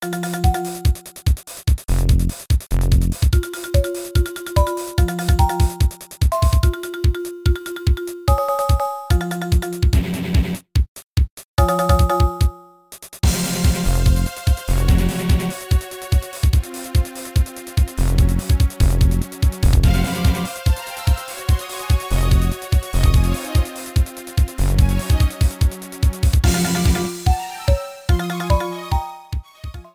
Boss music